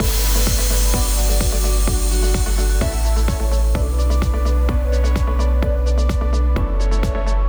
You are also missing a ton of notes.